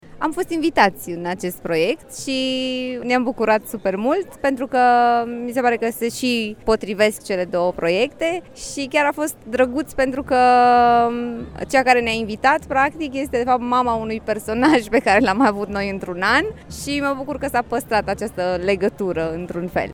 Personajele istorice au revenit în  cadrul Retro Marktplatz, eveniment încă în desfășurare, și care rememorează atmosfera târgurilor Brașovului de odinioară.